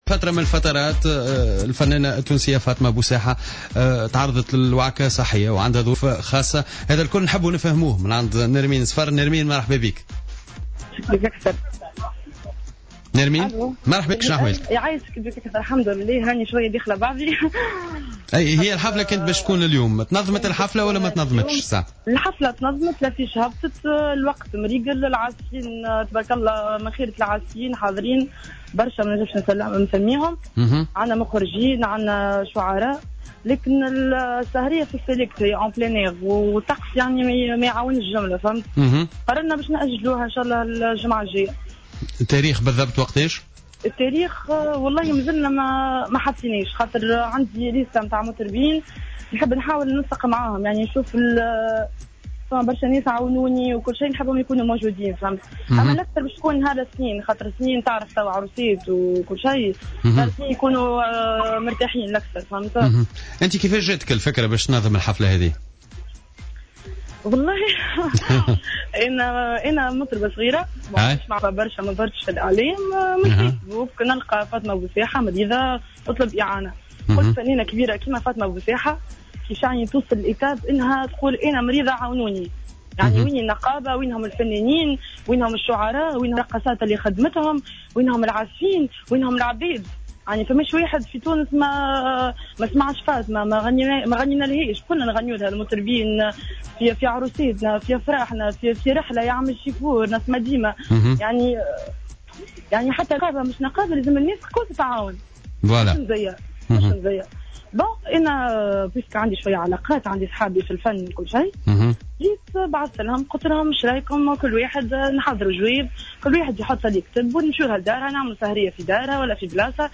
مداخلة لها في برنامج Jawhara By Night